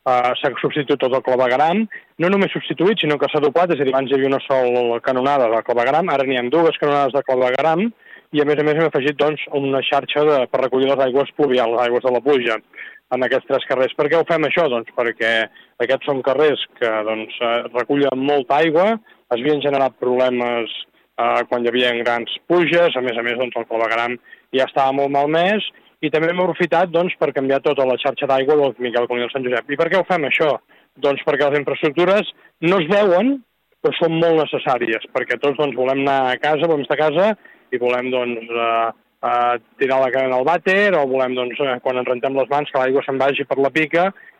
L’alcalde Marc Buch n’ha parlat a RCT.